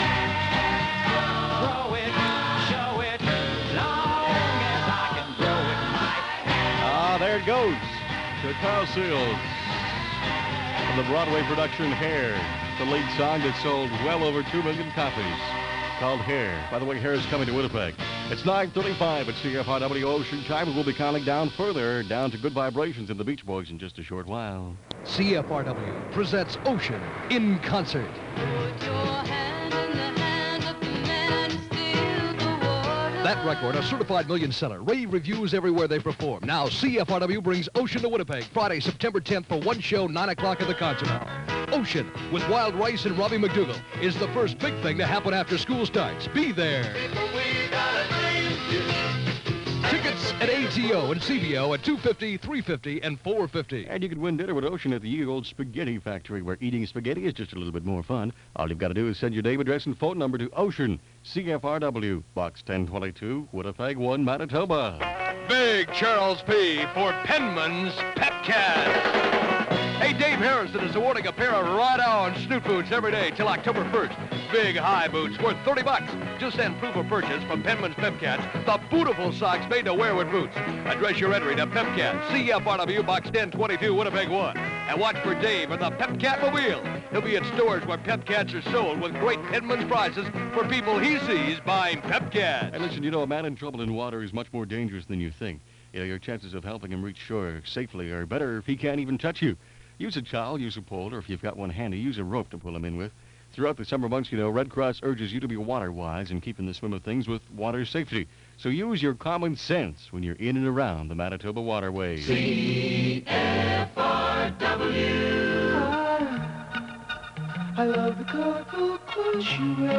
Winnipeg Radio in 1971